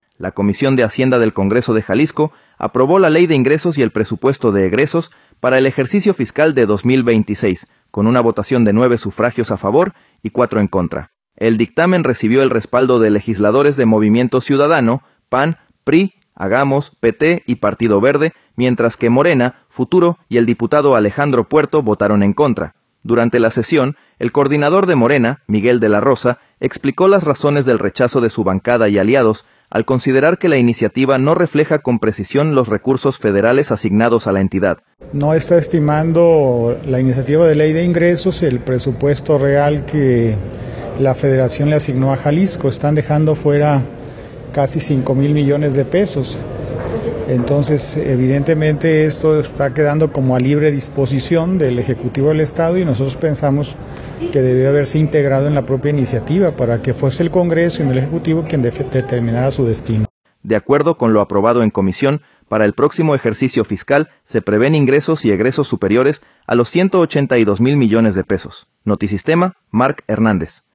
Durante la sesión, el coordinador de Morena, Miguel de la Rosa, explicó las razones del rechazo de su bancada y aliados, al considerar que la iniciativa no refleja con precisión los recursos federales asignados